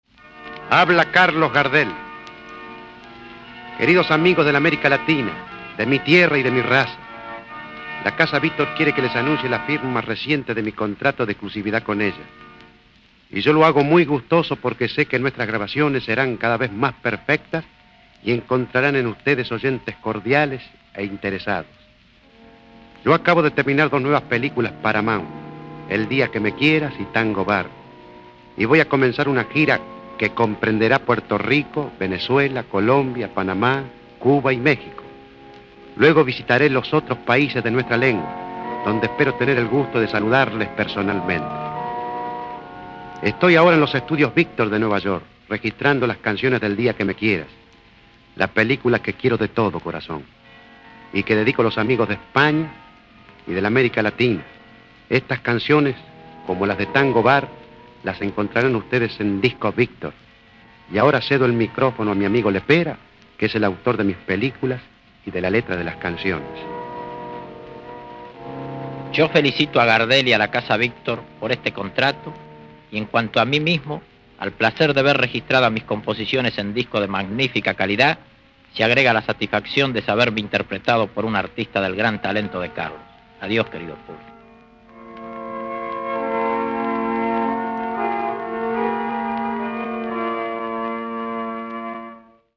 BBAJAR :Hablan Gardel y Lepera-(MP3)
Palabras de Gardel y Lepera.mp3